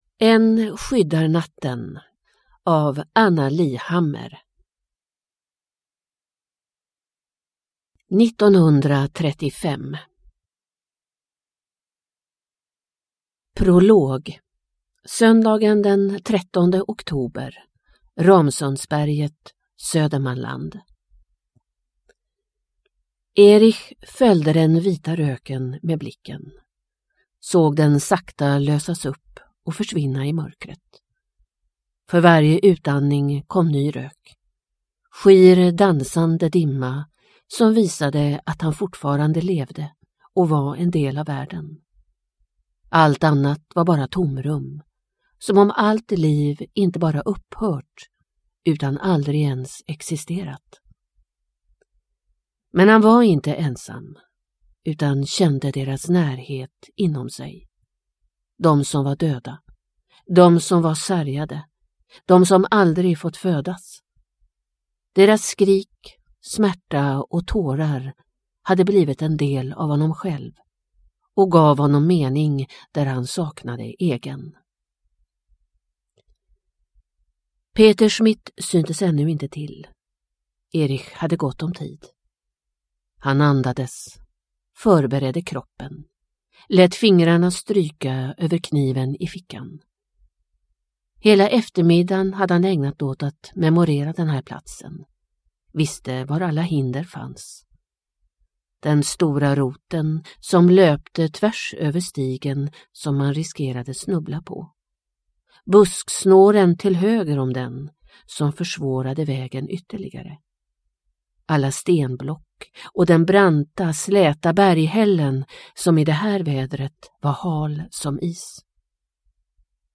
Än skyddar natten – Ljudbok – Laddas ner